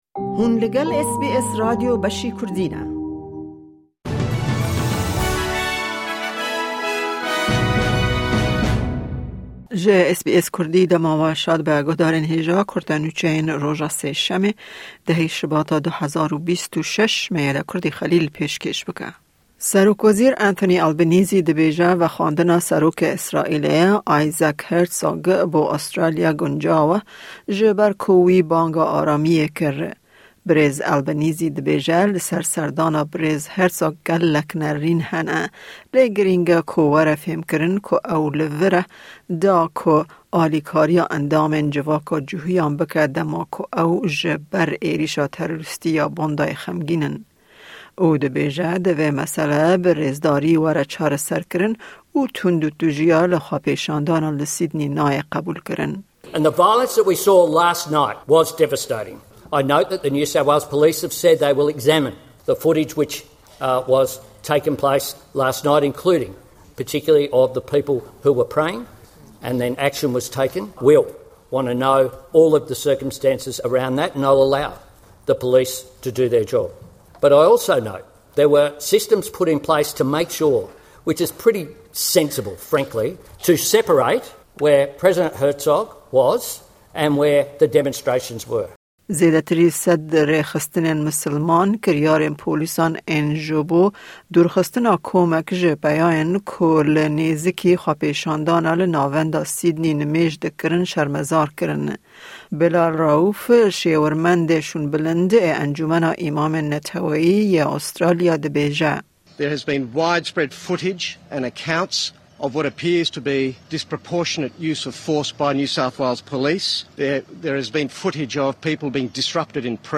Nûçeyên roja Sêşemê 10/02/2026